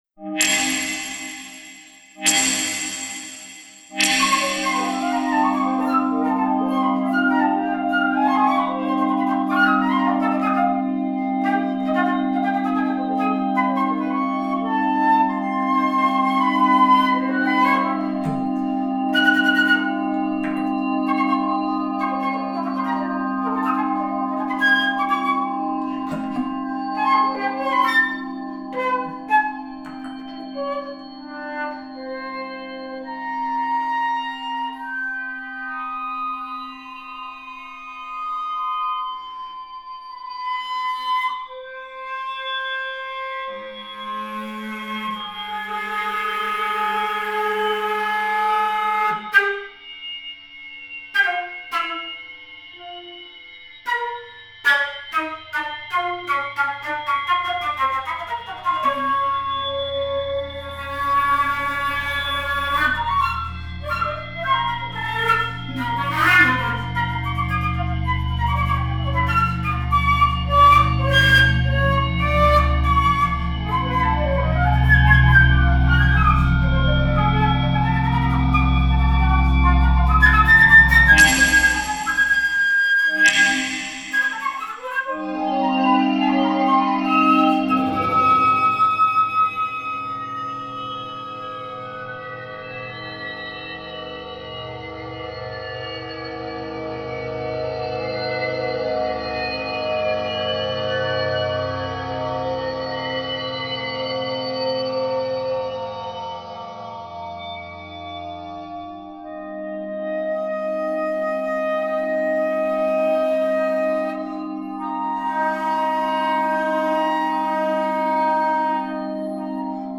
flute Score